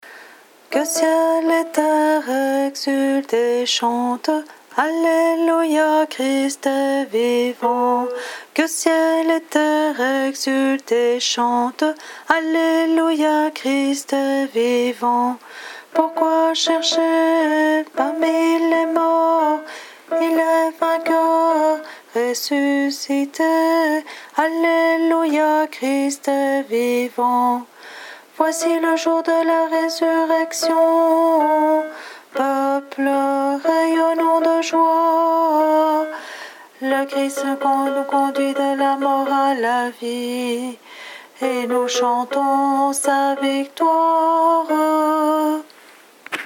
Voix chantée (MP3)COUPLET/REFRAIN
ALTO